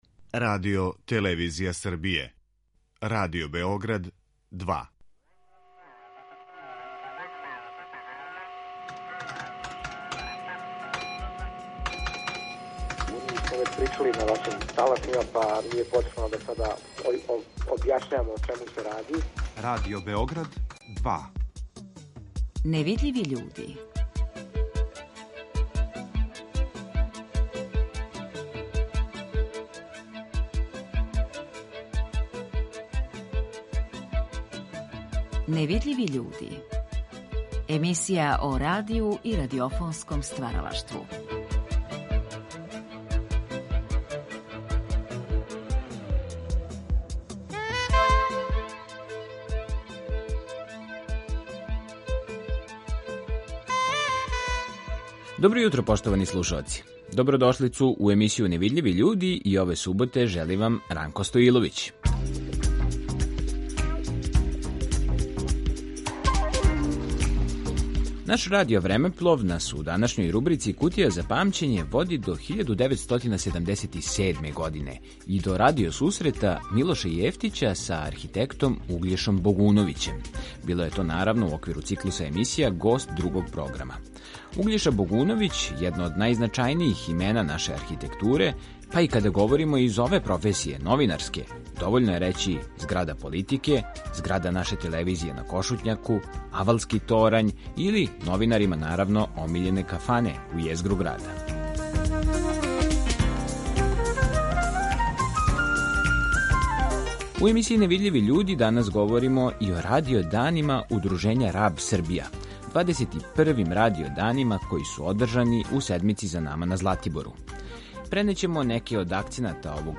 Представићемо и ситуацију у суседној Мађарској кроз разговор са учесницом једног од бројних панела
У редовној рубрици „Кутија за памћење" емитујемо одломке из разговора